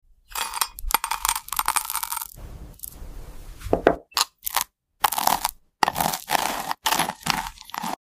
Tiny Gold Berry Spread Asmr Sound Effects Free Download